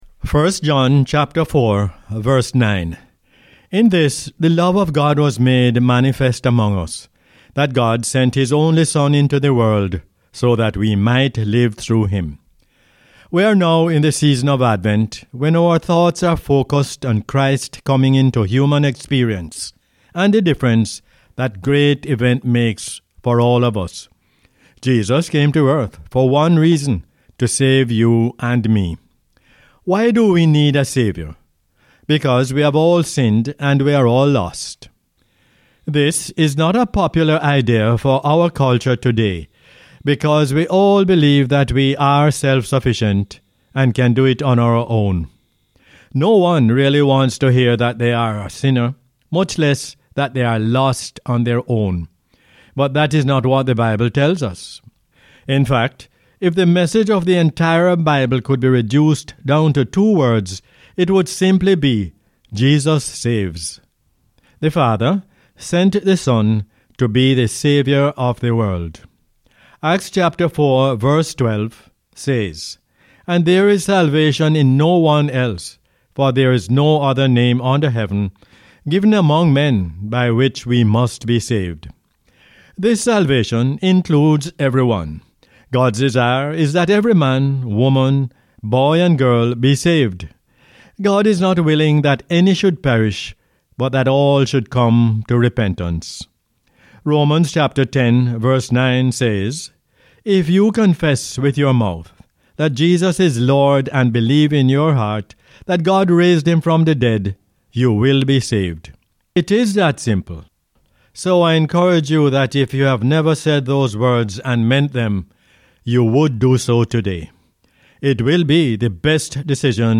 1 John 4:9 is the "Word For Jamaica" as aired on the radio on 4 December 2020.